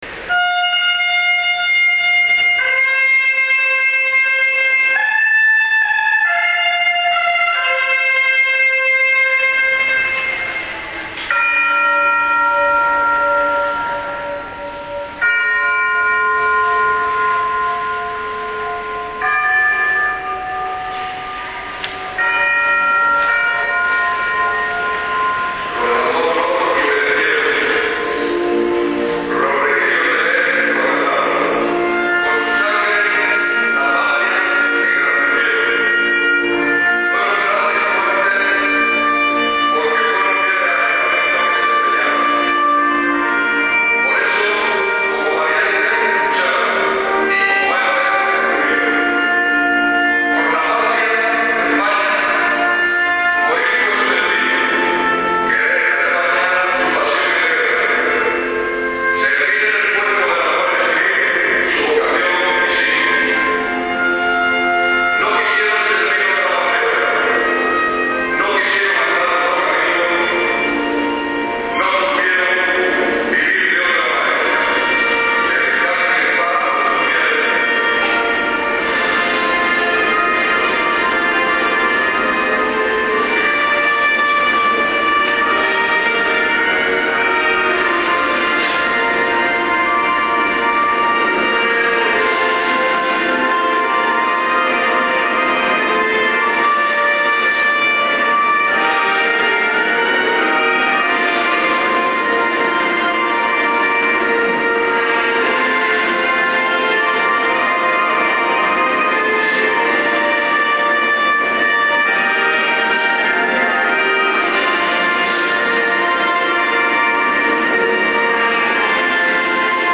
Misa día del Pilar - Guardia Civil 2016